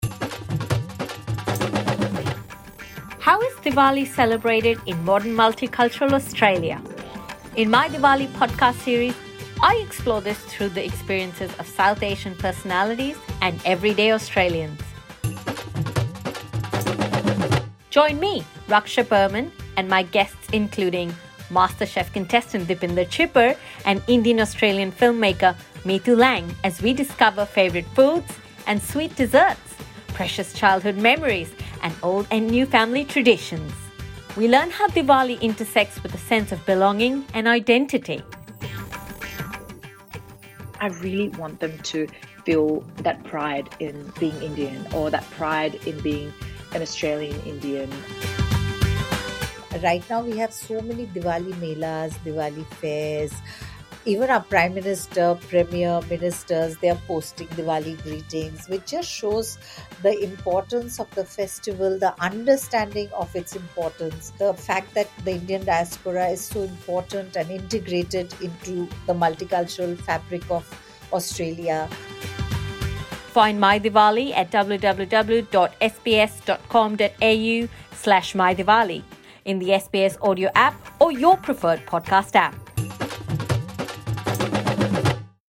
My Diwali features well known South Asian personalities and everyday Australians sharing their experiences of celebrating Diwali.